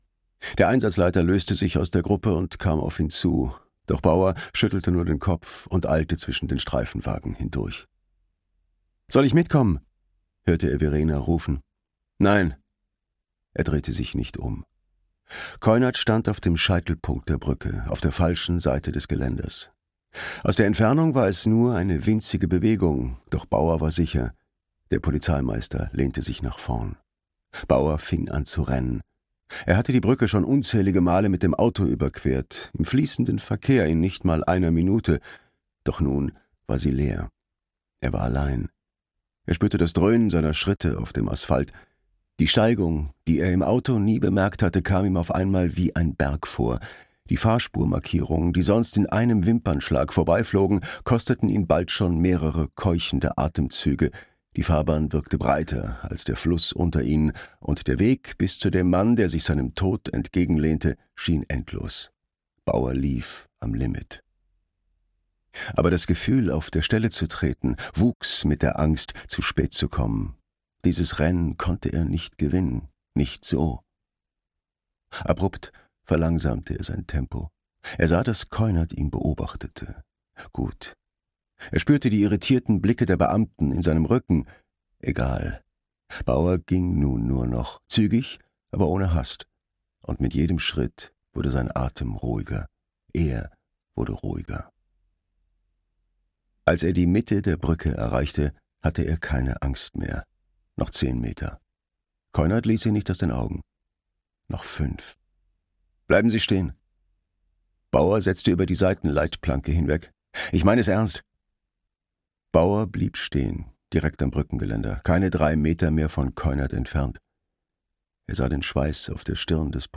Glaube Liebe Tod - Peter Gallert - Hörbuch